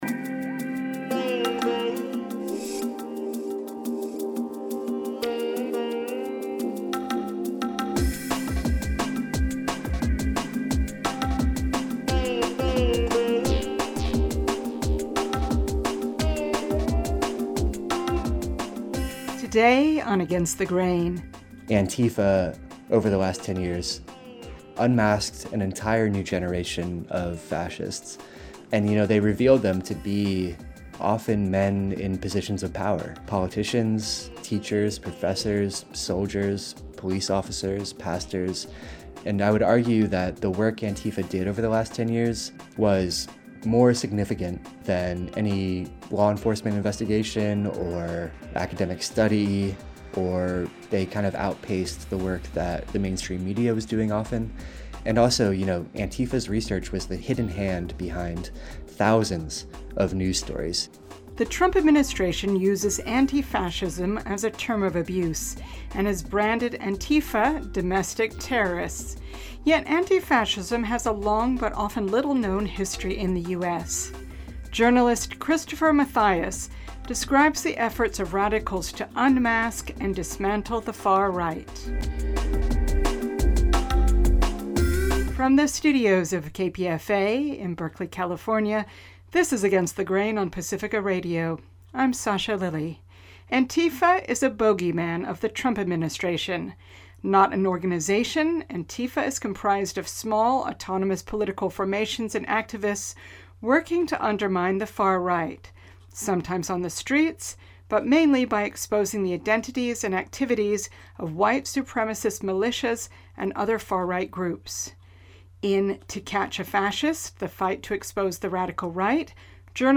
Democracy Now! is a daily independent award-winning news program hosted by journalists Amy Goodman and Juan Gonzalez.